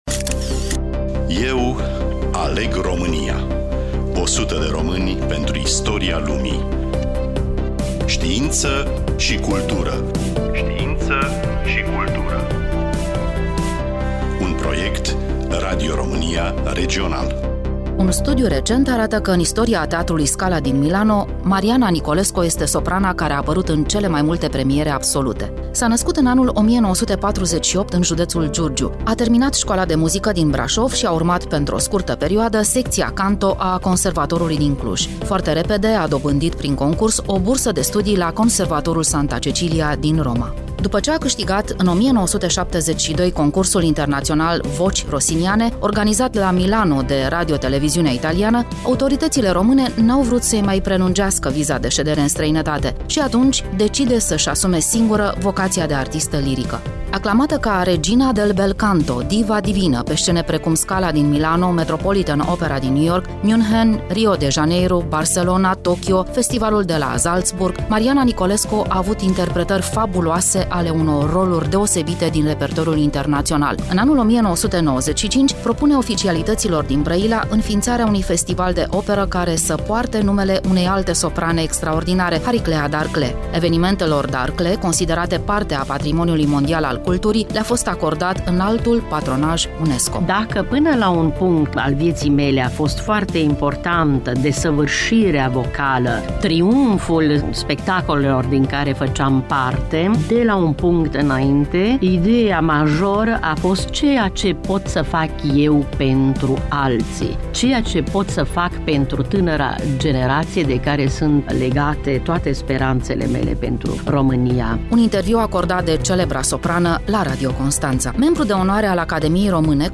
Fonoteca Radio Constanţa, interviu cu Mariana Nicolesco